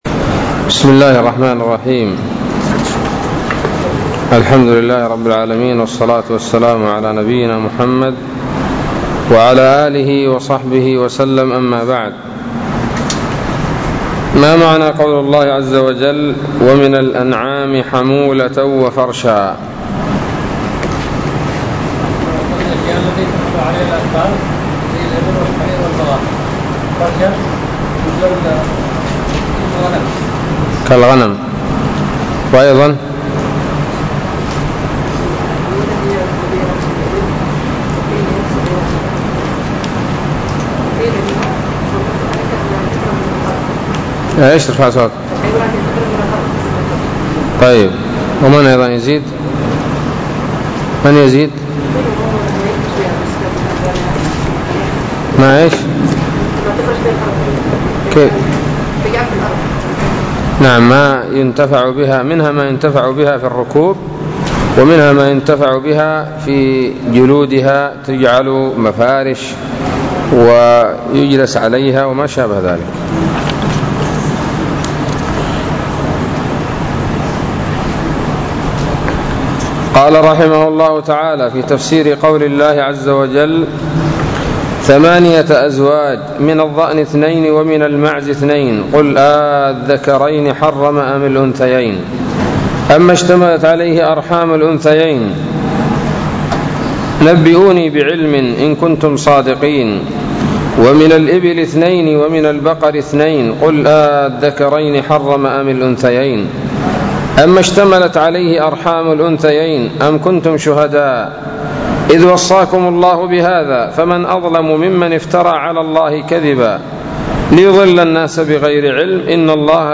006 سورة الأنعام الدروس العلمية تفسير ابن كثير دروس التفسير